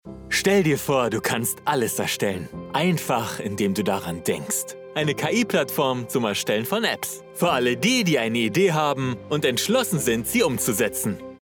Male
Approachable, Bright, Bubbly, Character
I record from my professionally treated home studio, delivering broadcast-ready audio.
Commercial_Cool.mp3
Microphone: Neumann TLM 103